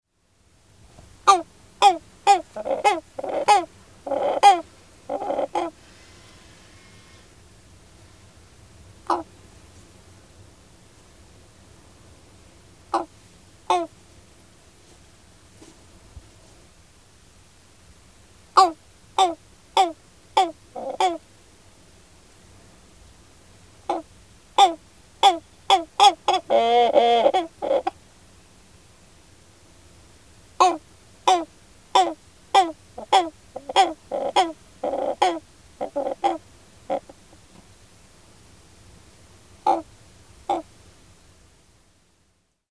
Voice.  At breeding colonies, gives various honks, sometimes tentative and brief, sometimes escalating to rapid series or drawn-out wails:
XC60091-Christmas-Shearwater-Puffinus-nativitatis.mp3